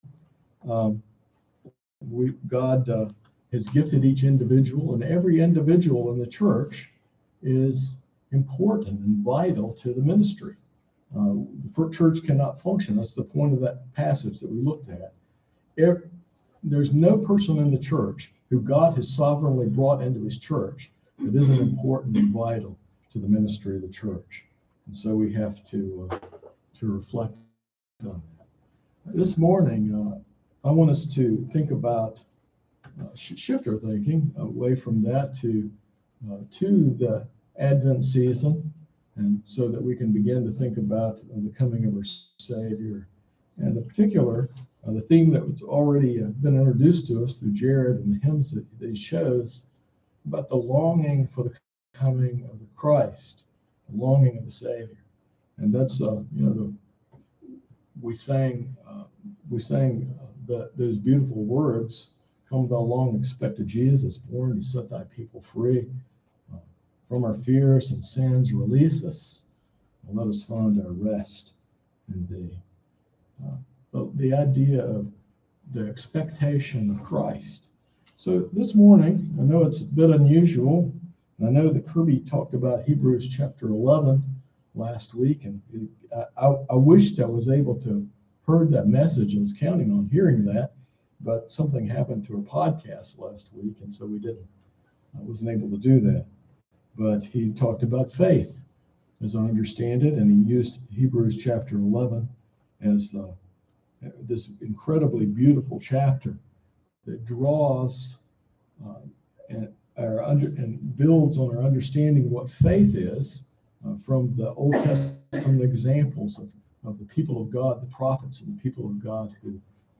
This sermon explores how God’s promises, foretold in the Old Testament through prophecies and ceremonies, are perfectly fulfilled in Jesus Christ, the central theme of the entire Bible.